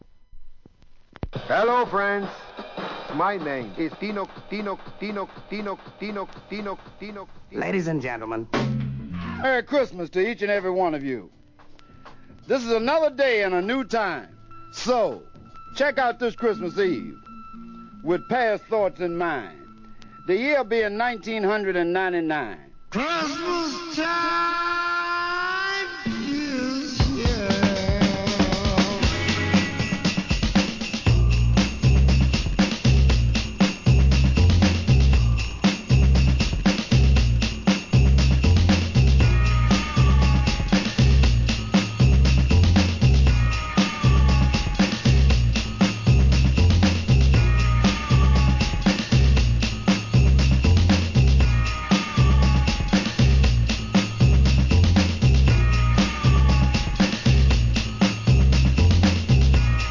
HIP HOP/R&B
A面はクリスマスにちなんだサンプルを使ったブレイクビーツを収録、B面にはクリスマスを意識したオモロ効果音を多数収録!